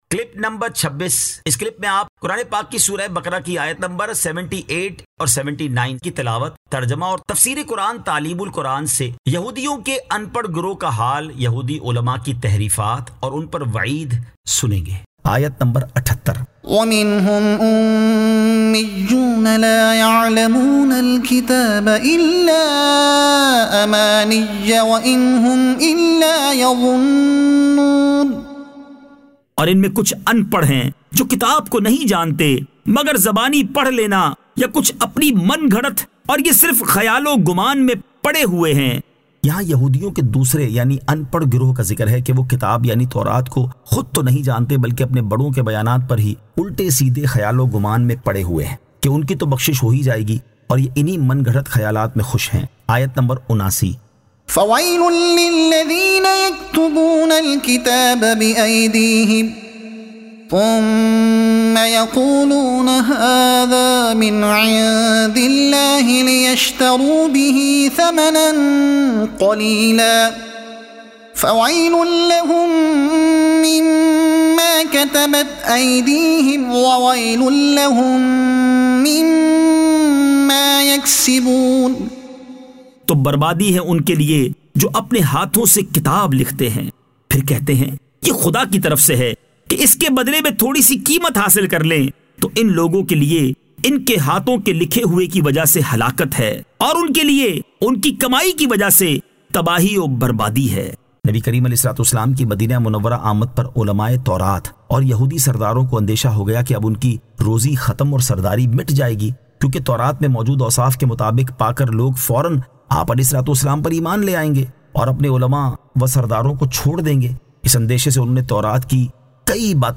Surah Al-Baqara Ayat 78 To 79 Tilawat , Tarjuma , Tafseer e Taleem ul Quran